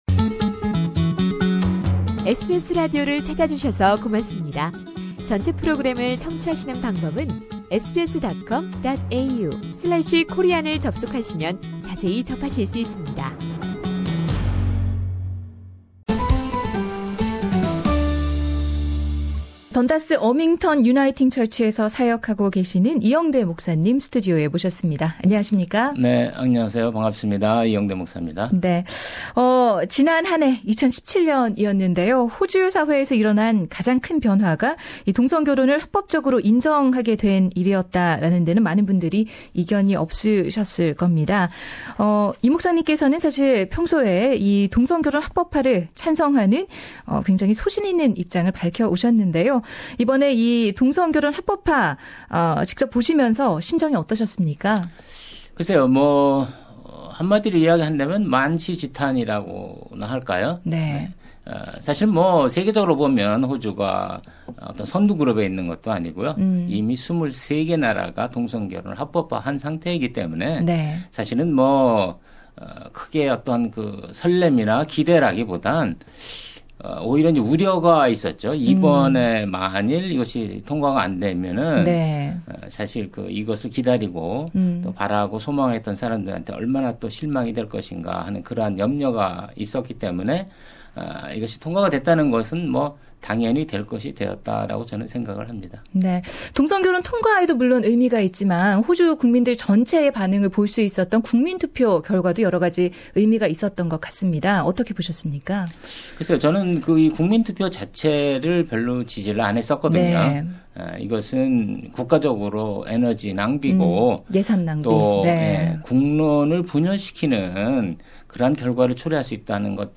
A full interview is available on Podcast above.